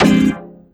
50SYNT01  -R.wav